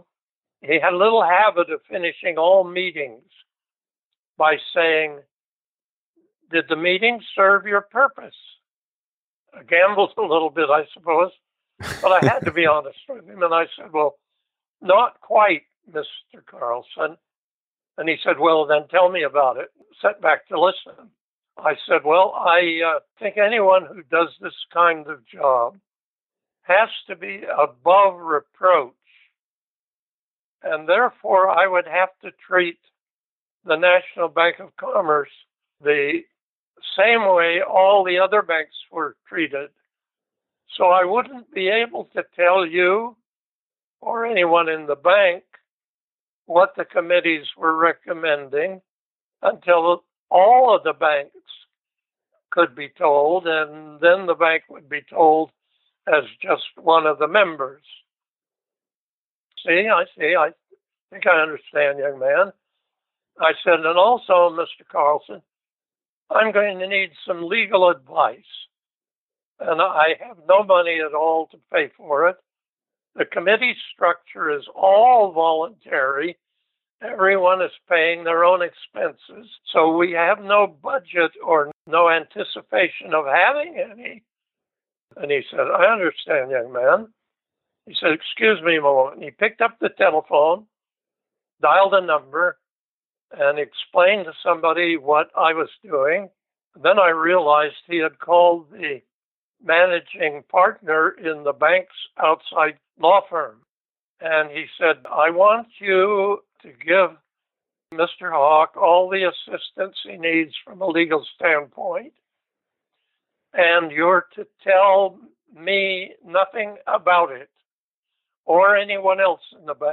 Here, Dee Hock (91 years old this year) speaks about a moment in 1970, Dee was a 41-year-old bank manager at The National Bank of Commerce and had experimented with self-organisation and was now trying to form a new form of collaborative organisation between banks for a credit card – one that would become VISA, the largest commercial enterprise on earth at some point.